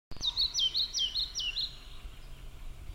Птицы -> Мухоловковые ->
мухоловка-пеструшка, Ficedula hypoleuca
Ziņotāja saglabāts vietas nosaukumsDaļēji izcirsts mežs
СтатусПоёт